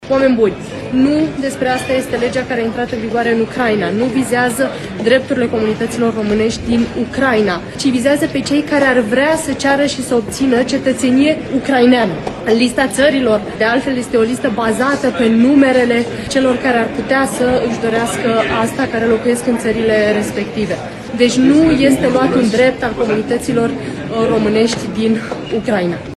Ministrul de Externe, Oana Țoiu, spune că românii nu sunt vizați de acest act normativ.
Ministrul de Externe, Oana Țoiu: „Nu vizează drepturile comunităților românești din Ucraina”